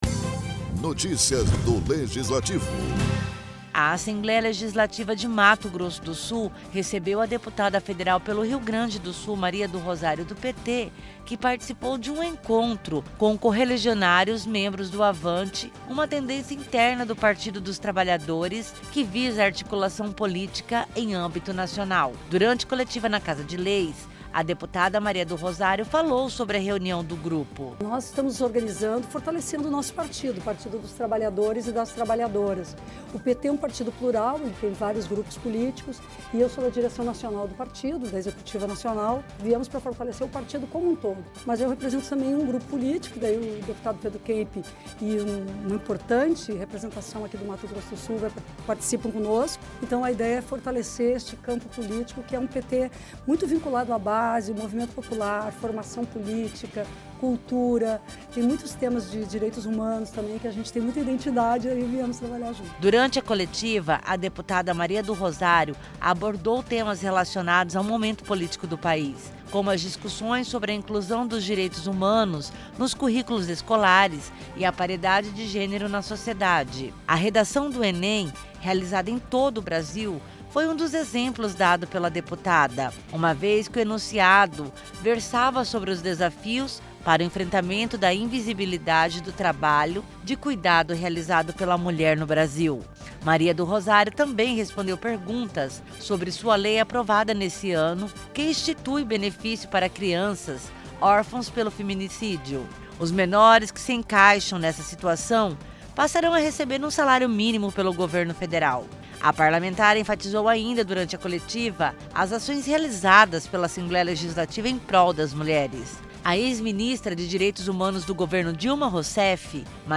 Na ALEMS a deputada Federal Maria do Rosário fala sobre o encontro com correligionários membros do avante, uma tendência interna do Partido dos Trabalhadores (PT), que visa a articulação em âmbito nacional para retomar a política de base do partido.